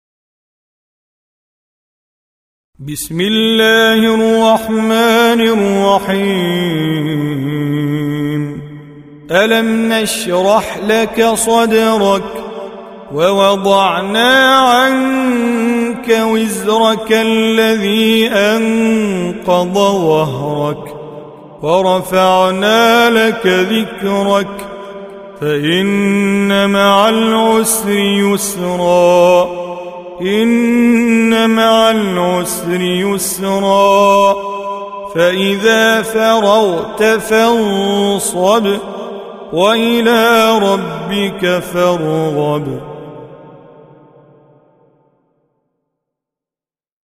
94. Surah Ash-Sharh سورة الشرح Audio Quran Tajweed Recitation
Surah Repeating تكرار السورة Download Surah حمّل السورة Reciting Mujawwadah Audio for 94.